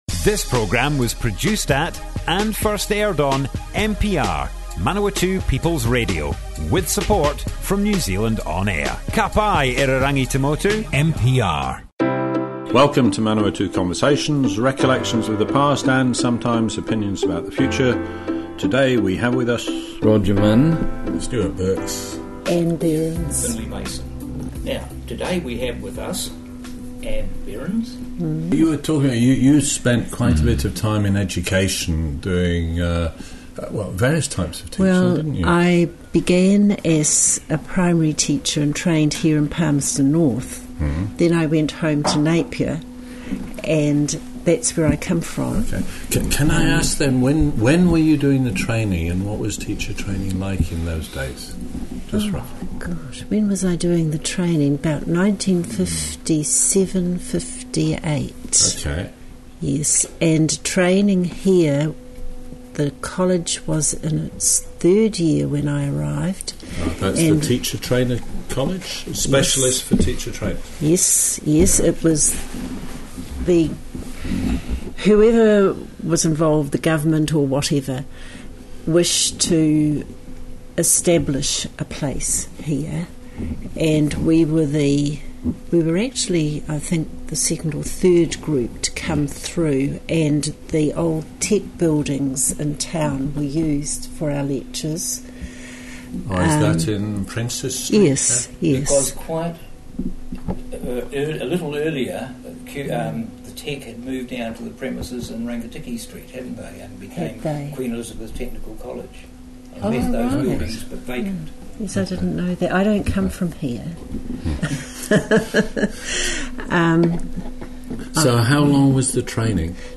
Manawatu Conversations More Info → Description Broadcast on Manawatu People's Radio 28 August 2018.
oral history